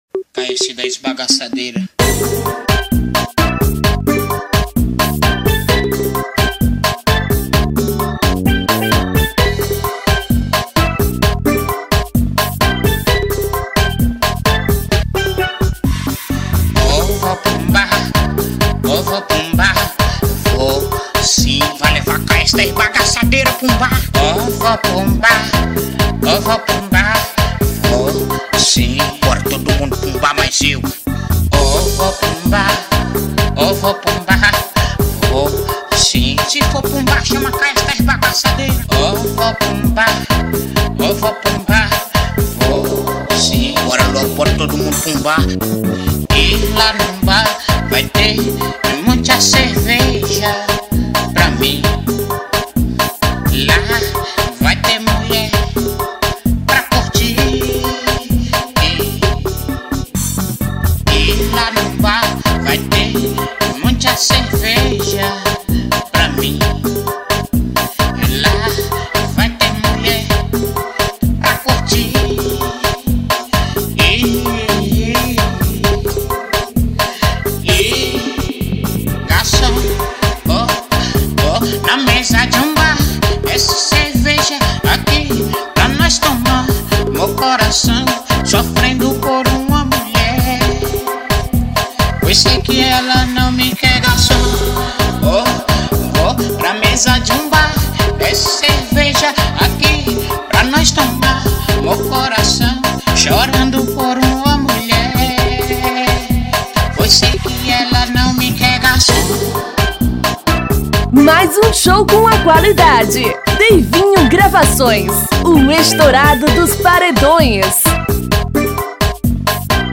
2024-06-16 20:06:51 Gênero: MPB Views